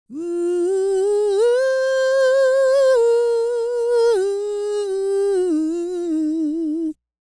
E-CROON 3021.wav